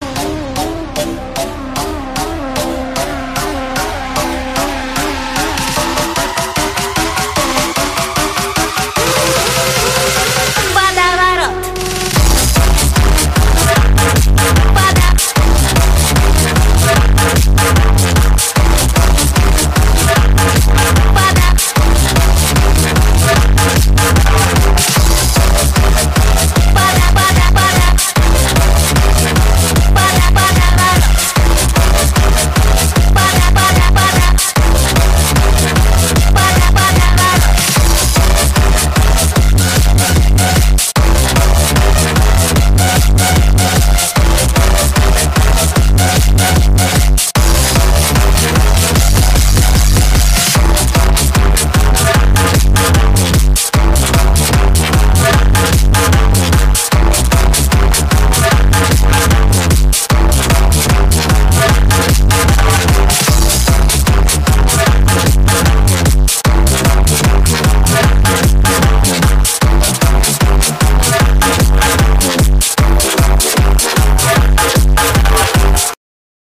• Качество: 267, Stereo
громкие
жесткие
мощные басы
Стиль: хардбасс